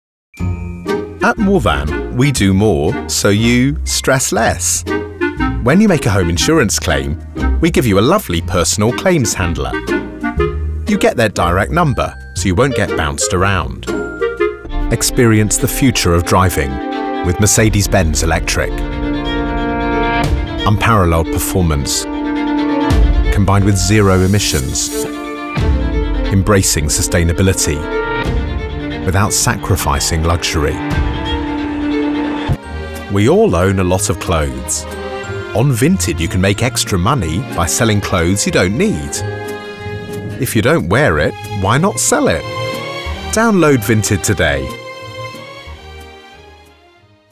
Inglés (Reino Unido)
Autoritario
Conversacional
Seguro